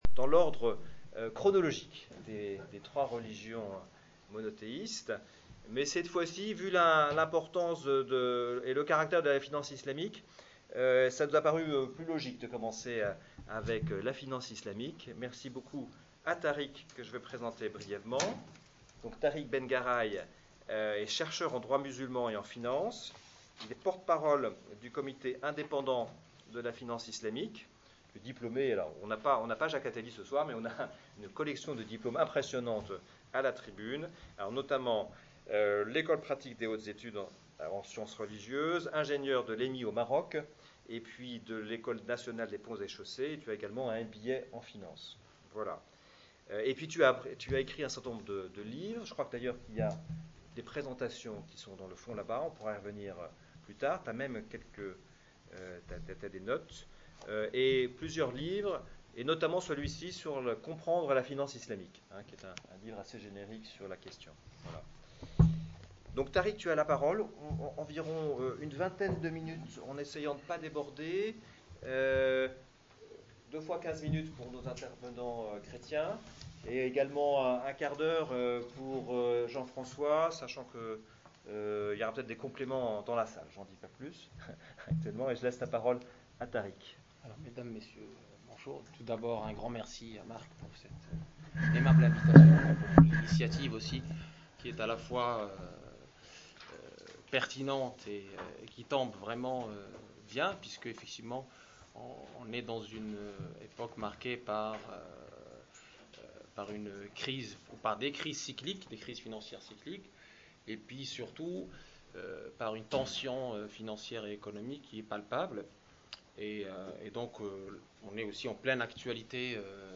Visionnez les extraits des vidéos de la conférence débat du mardi 3 avril 2012 sur le thème :